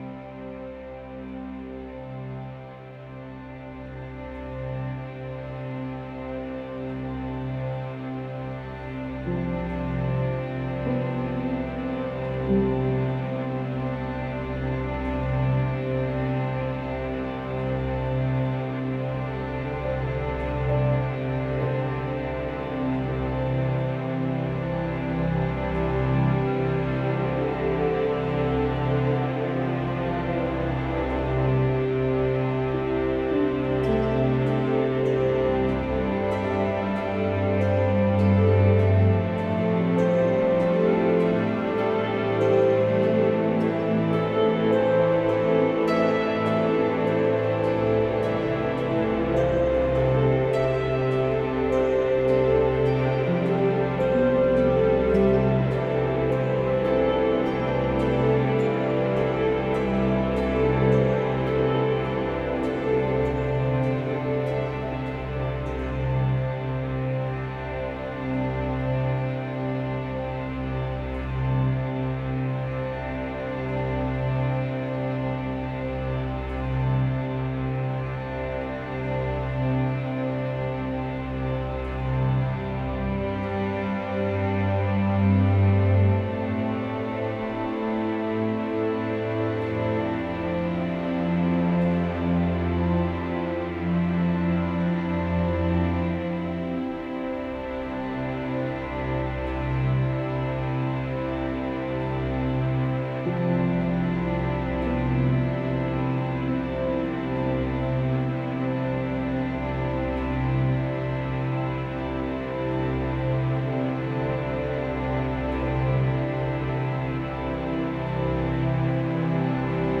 Tranquil & Ambiance